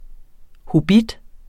Udtale [ hoˈbid ]